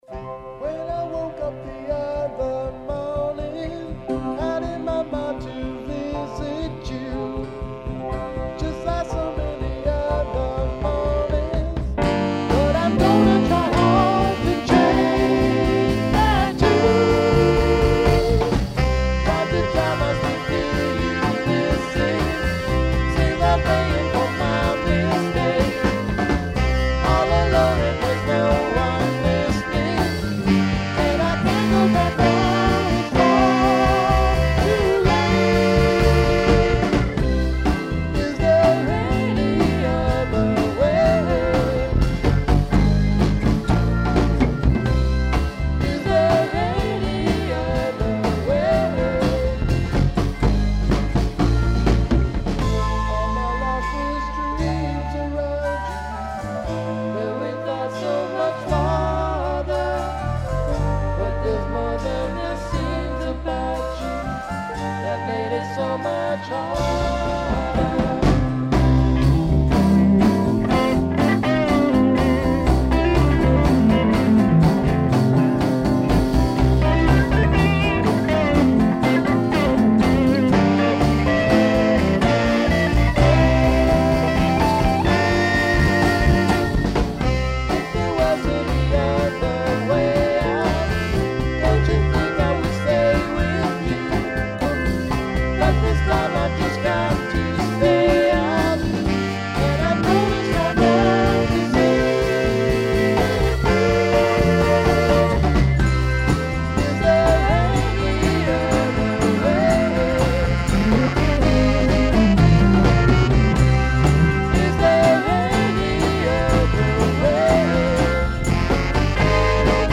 Back Vocals
Guitar Solo
Bass
Drums
Sax, Flute